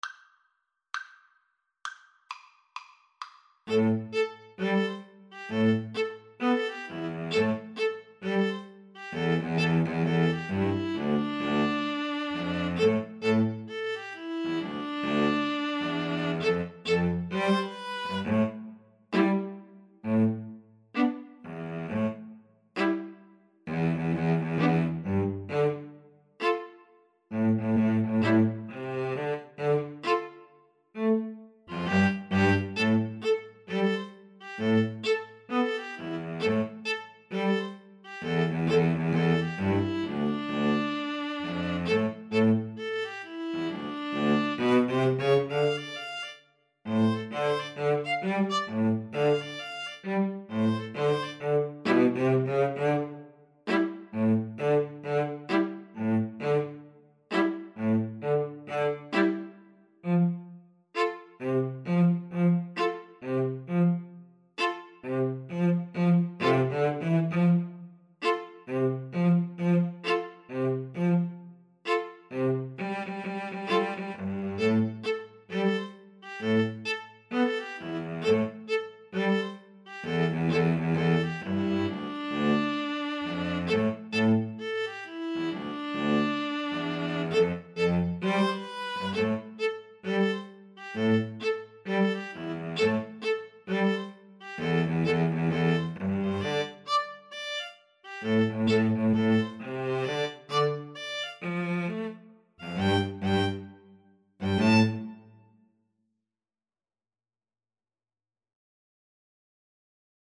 A funky hop-hop style piece.
String trio  (View more Intermediate String trio Music)
Pop (View more Pop String trio Music)